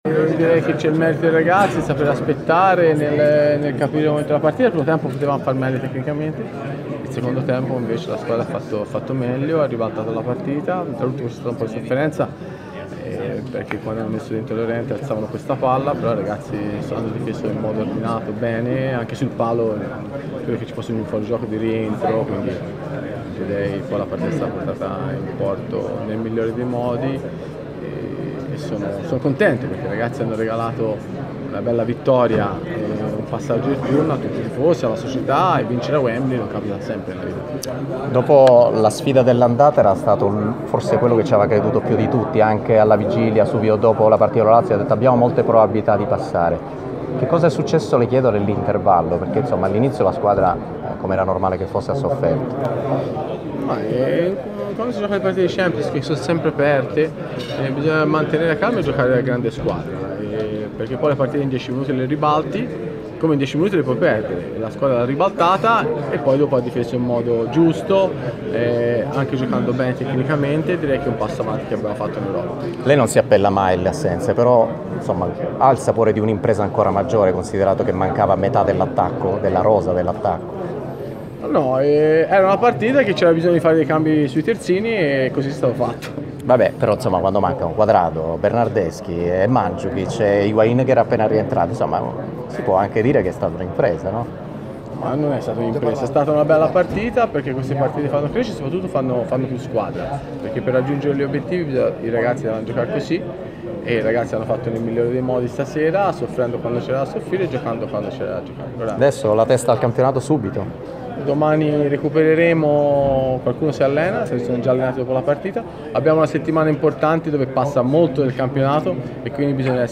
Le parole di Massimiliano Allegri ai microfoni di RMC Sport in zona mista nel post partita di Tottenham-Juventus: "Nel primo tempo potevamo far meglio tecnicamente, nel secondo tempo invece la squadra ha fatto meglio e ha ribaltato la partita.
Intervista